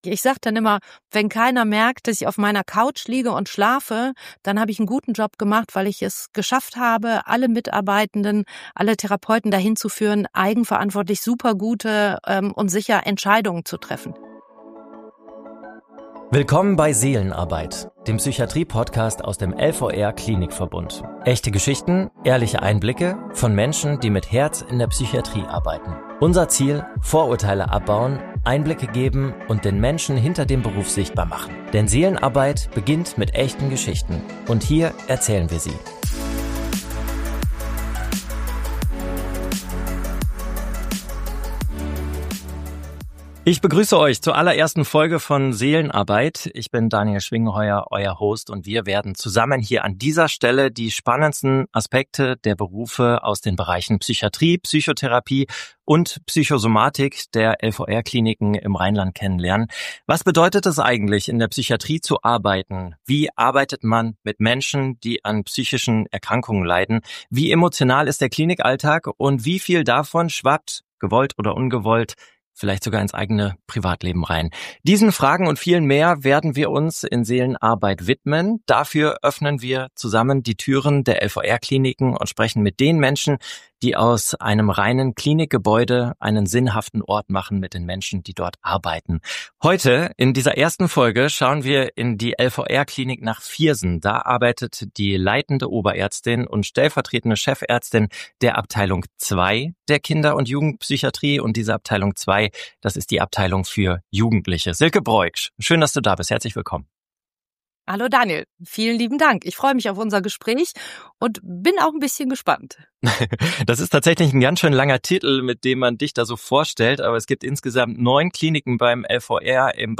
erzählt offen, authentisch und mit spürbarer Leidenschaft von Herausforderungen, Chancen und Möglichkeiten ihres Berufs.